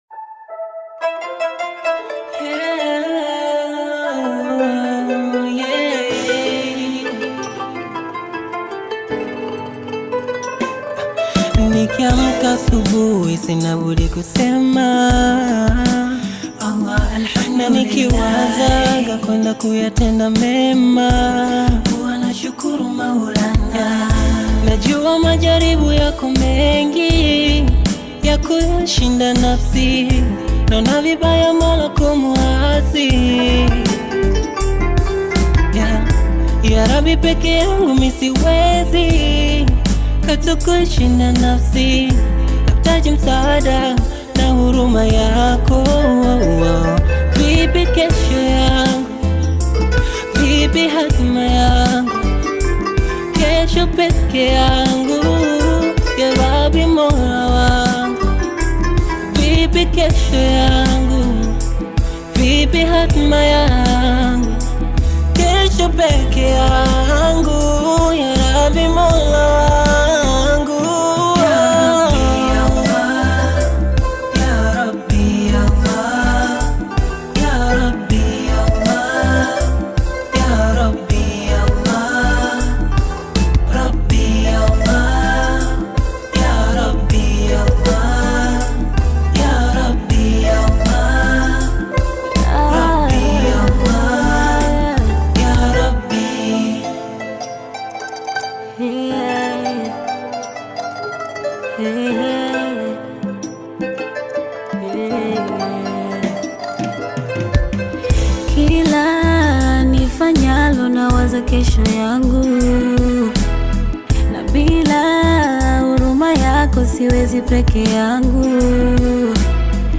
Genre: Qaswida